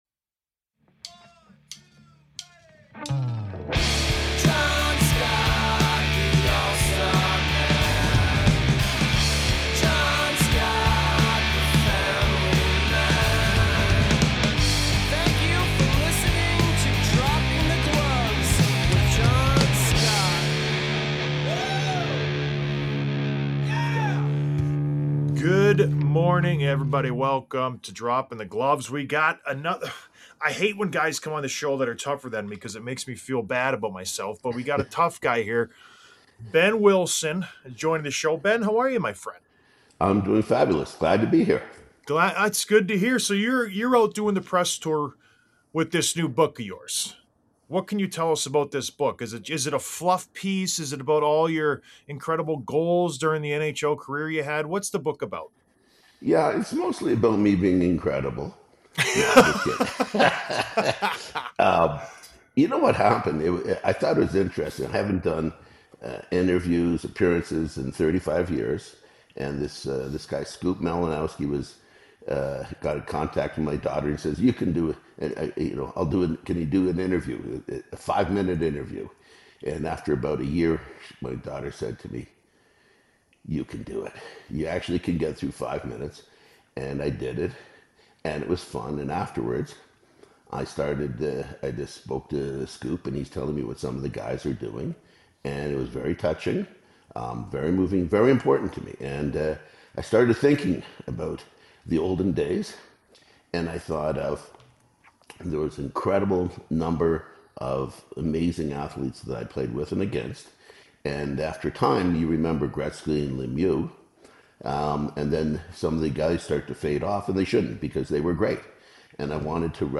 Interview w/ Behn Wilson